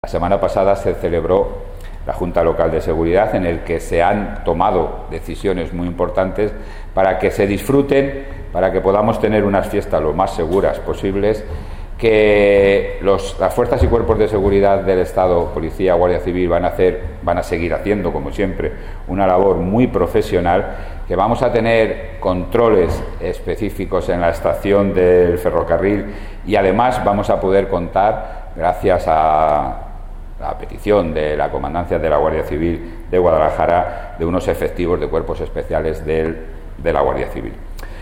Declaraciones del alcalde José Luis Blanco 1 Declaraciones del alcalde José Luis Blanco 2